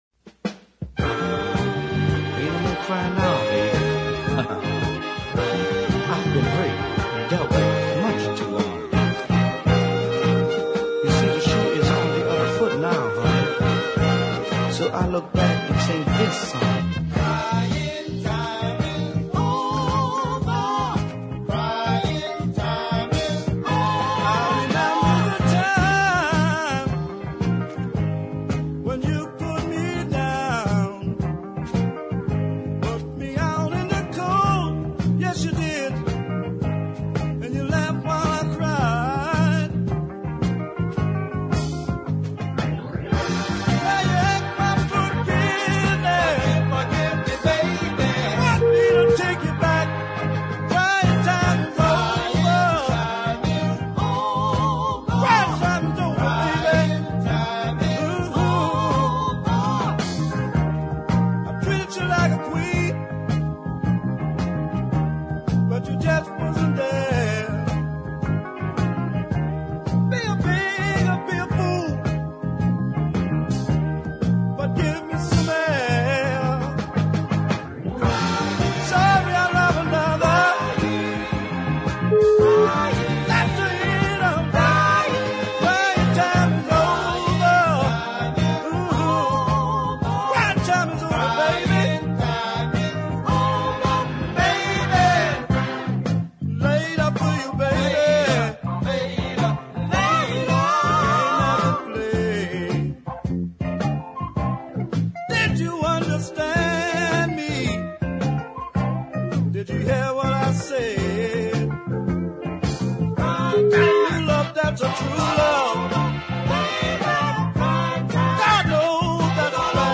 strong vocal and the excellent harmonies behind him